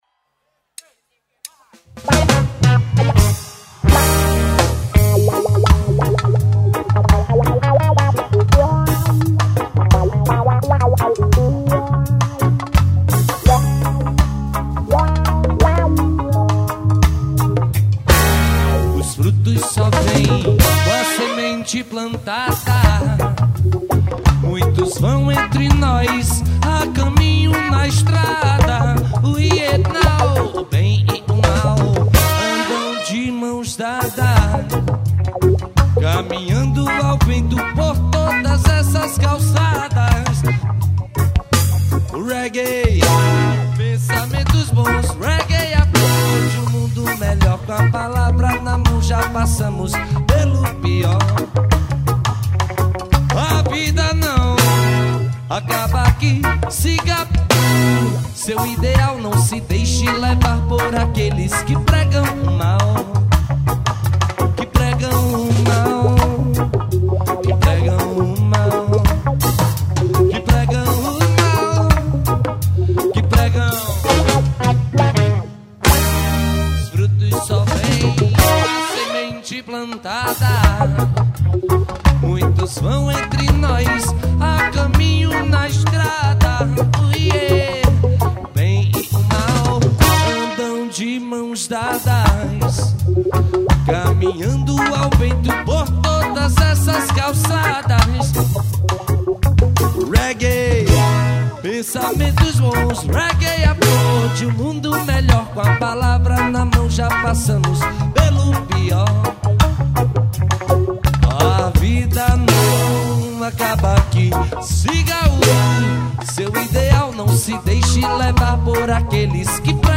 AO VIVO
2121   02:23:00   Faixa:     Reggae
Guitarra, Voz
Escaleta, Teclados
Bateria
Trompete, Vocal
Sax Alto
Baixo Elétrico 6